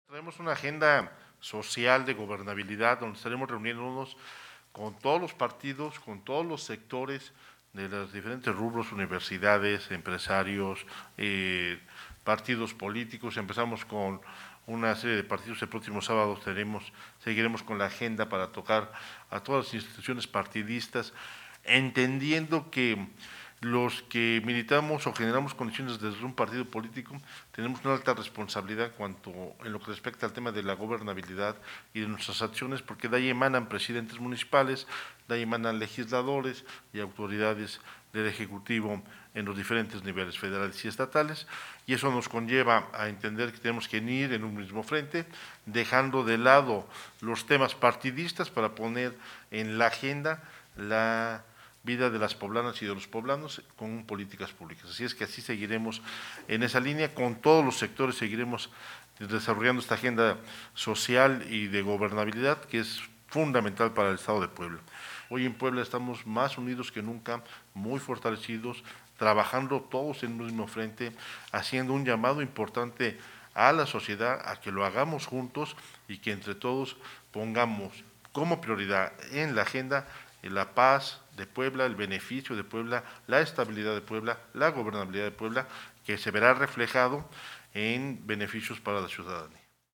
En rueda de prensa el mandatario mencionó que será de suma importancia la visita del funcionario, pues de esta manera se generan lazos con el gobierno federal y siempre serán bienvenidos a la entidad.